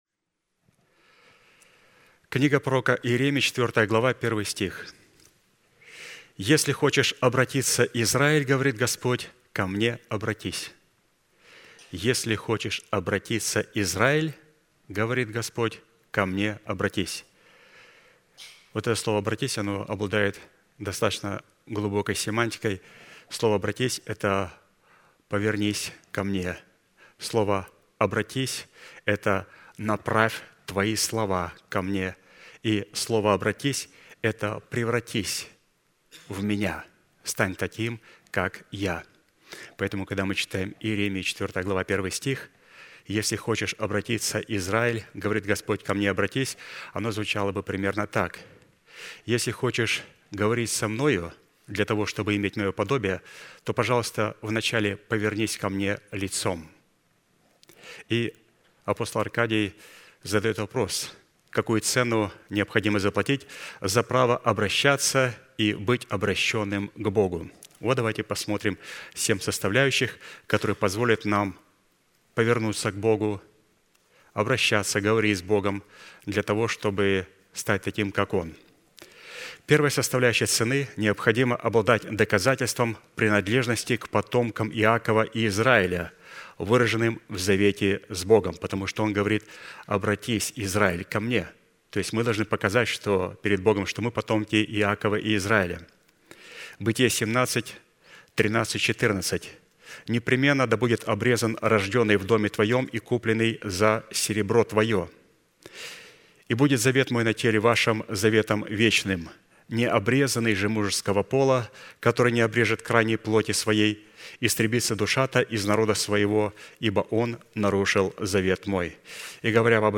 Sermon title: Tithes